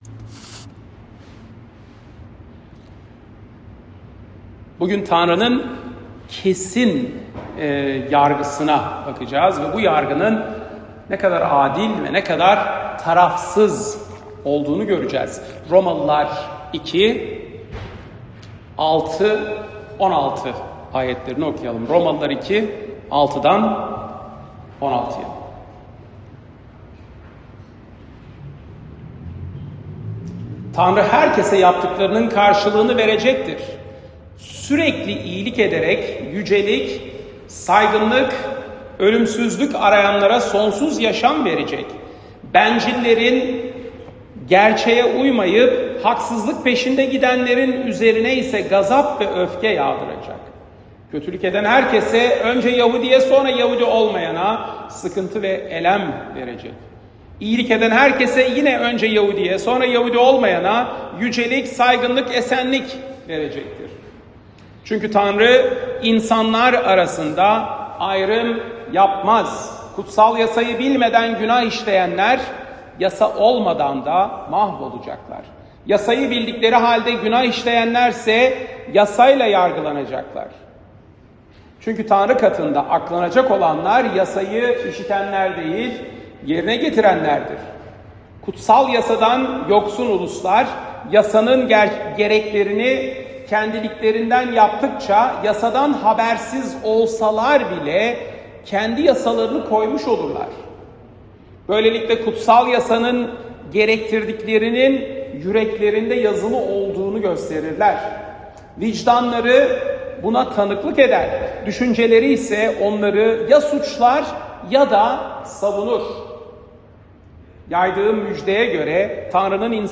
Salı, 1 Nisan 2025 | Romalılar Vaaz Serisi 2024-26, Vaazlar